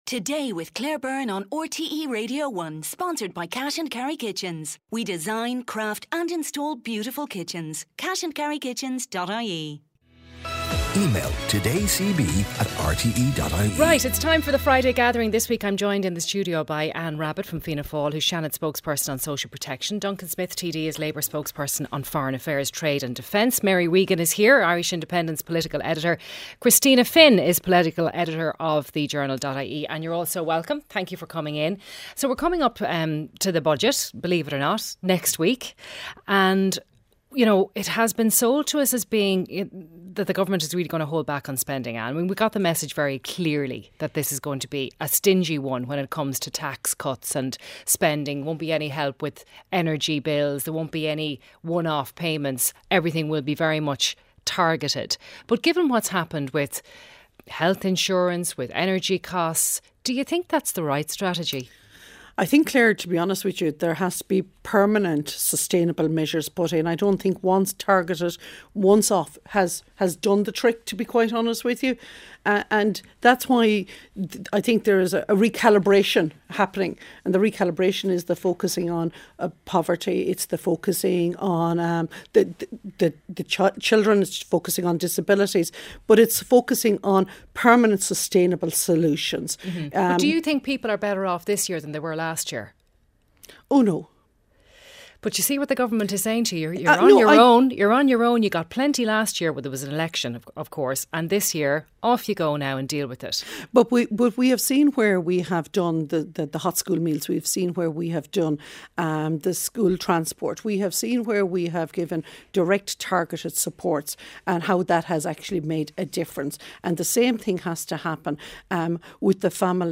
Highlights from the mid-morning show with Claire Byrne, featuring stories of the day, sharp analysis, features, sports and consumer interest items.